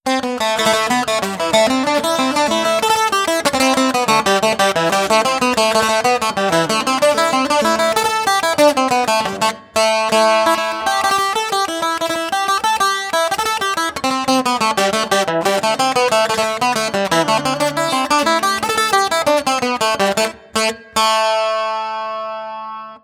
• irish bouzouki folk pickup sequence.wav
irish_bouzouki_folk_pickup_sequence_5XE.wav